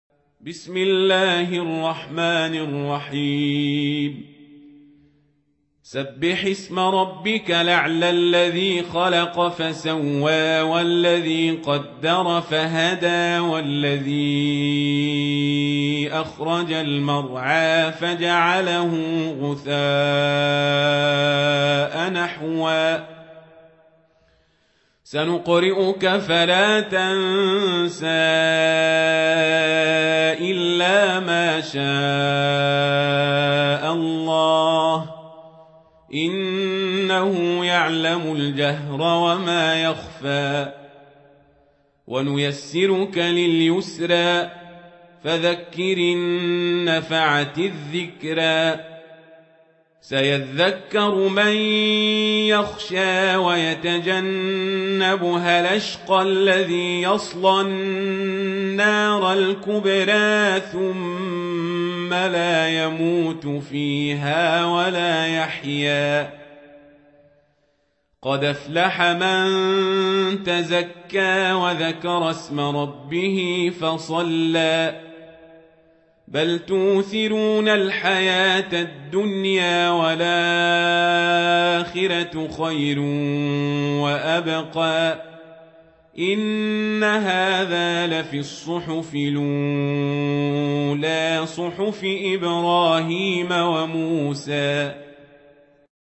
سورة الأعلى | القارئ عمر القزابري